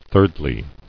[third·ly]